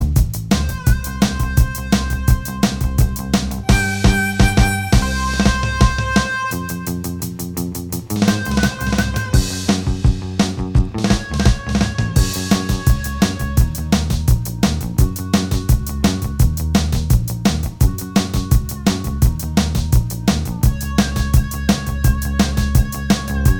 Minus Lead Guitar Rock 1:49 Buy £1.50